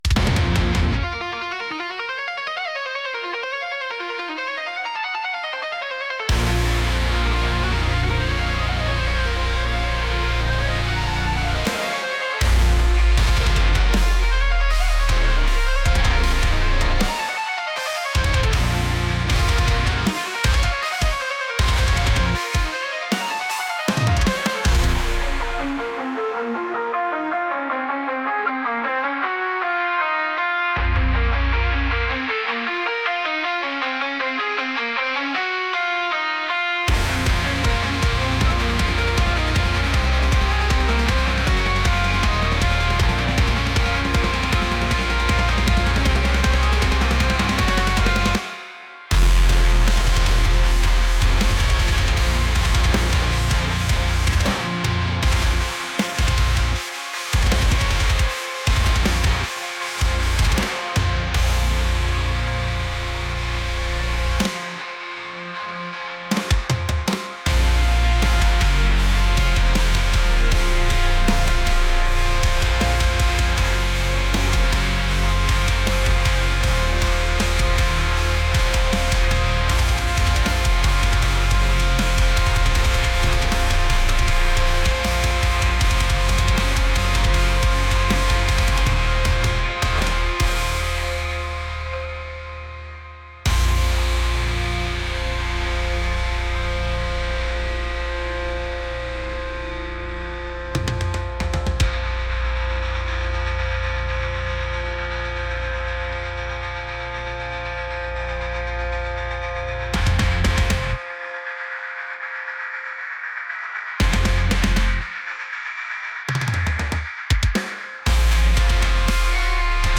aggressive | heavy